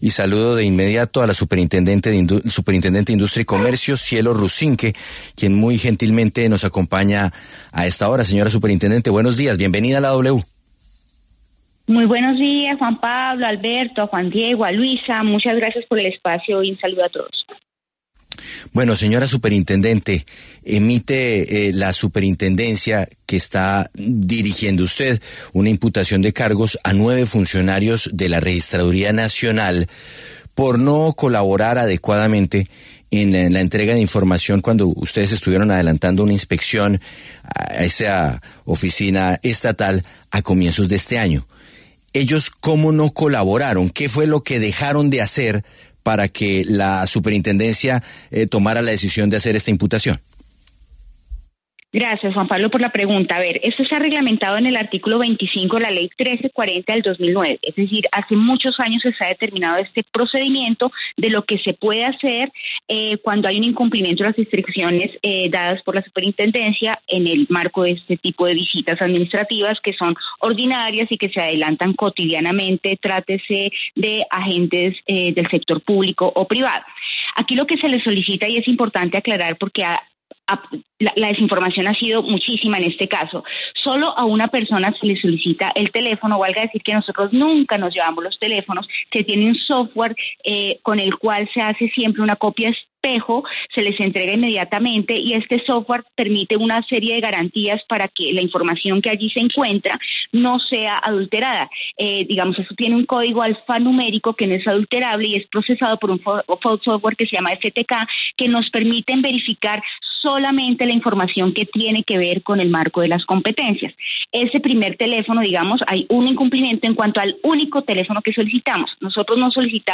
La superintendente de Industria y Comercio, Cielo Rusinque, se pronunció en La W sobre la polémica tras abrir una investigación a funcionarios de la Registraduría que no habrían entregado información en el marco de unas visitas que realiza esa entidad.